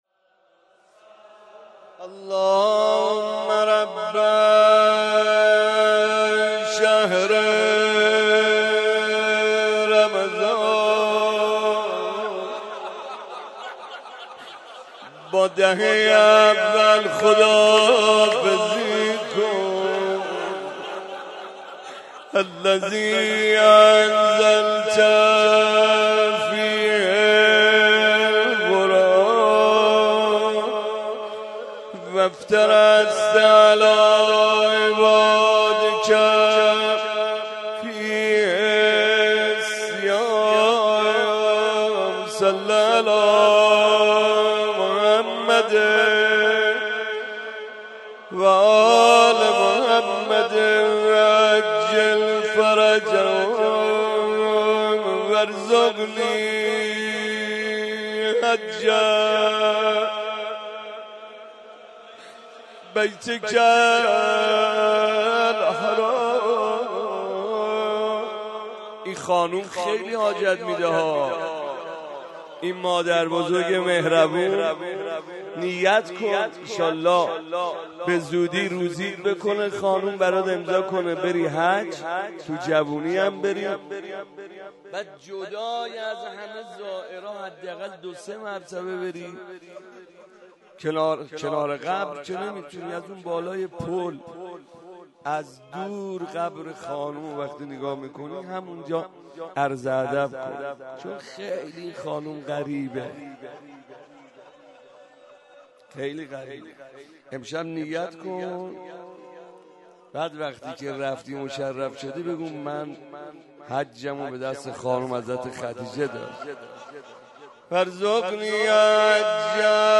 مناجات
مناسبت : شب دهم رمضان - وفات حضرت خدیجه
قالب : مناجات